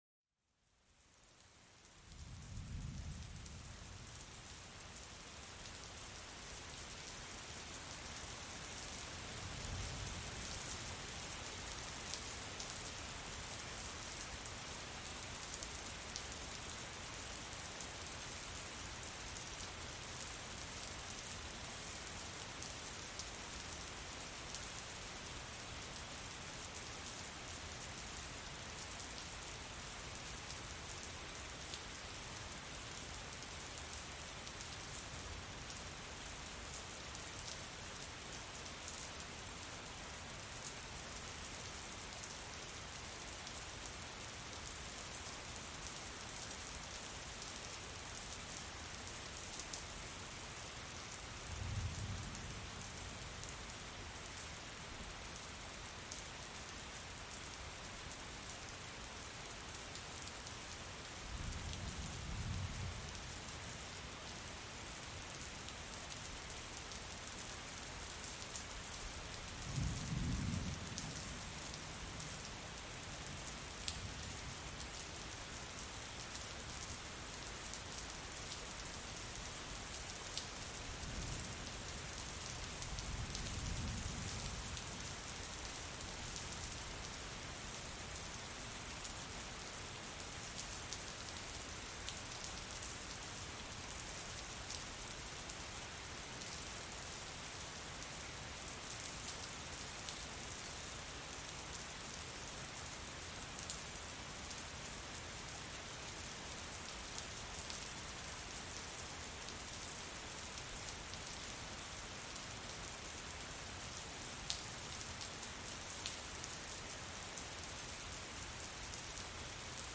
En este episodio especial, exploramos la dulce melodía de la lluvia, una sinfonía natural que calma la mente y rejuvenece el alma. Escucha cómo cada gota de agua teje un tapiz de tranquilidad, envolviéndote en un capullo de serenidad sin igual.
SONIDOS DE LA NATURALEZA PARA LA RELAJACIÓN